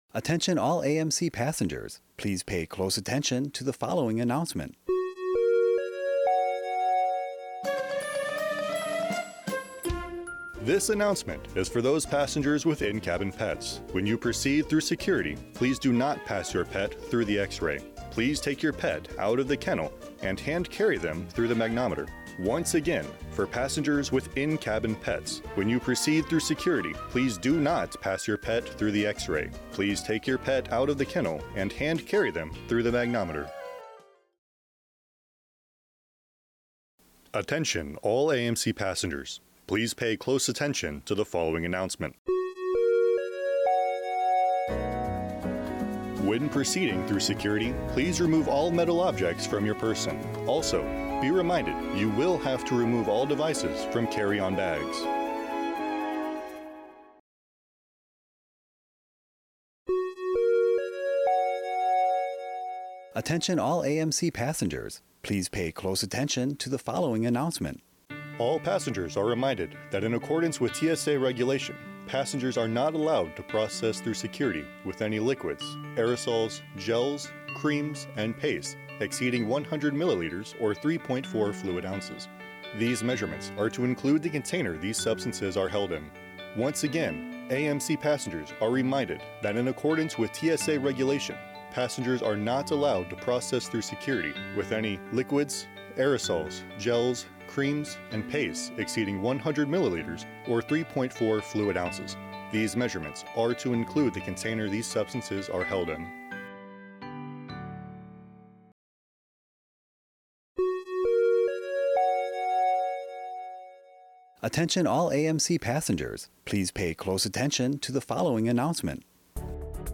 This readout contains messaging about TSA Regulations, securing your luggage, protecting your pets and tips for moving through security efficiently, narrated by service members assigned to Media Bureau Japan.